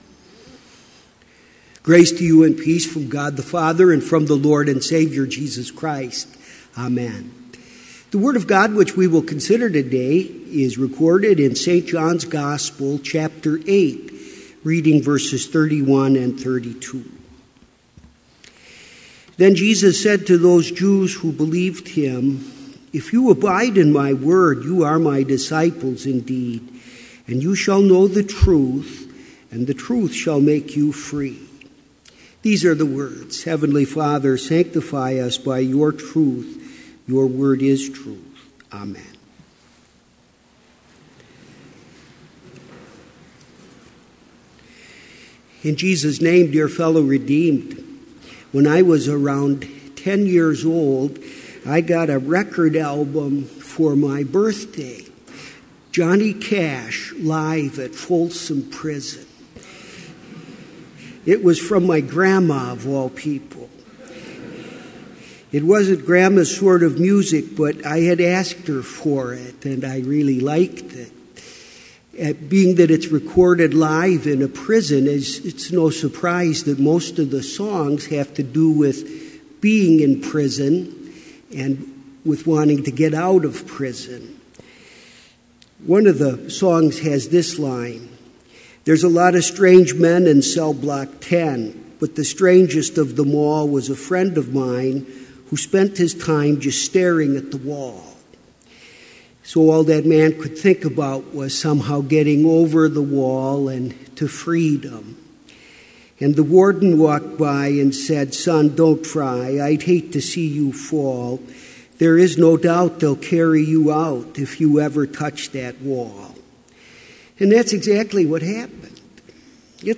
Complete Service
• Hymn 234, We Have a Sure Prophetic Word
• Devotion
This Chapel Service was held in Trinity Chapel at Bethany Lutheran College on Thursday, February 8, 2018, at 10 a.m. Page and hymn numbers are from the Evangelical Lutheran Hymnary.